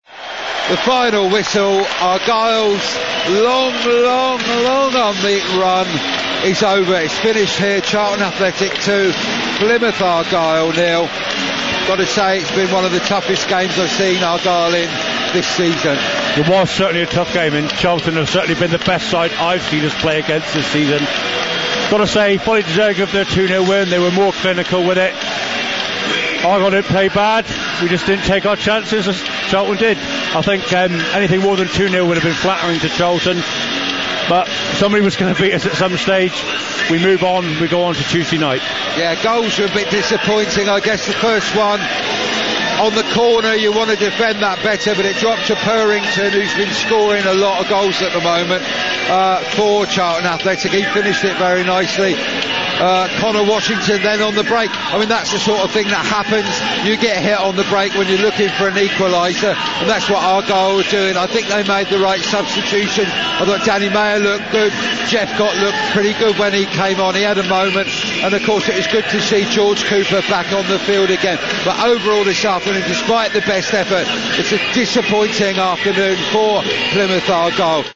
Final whistle reaction from the Plymouth audio.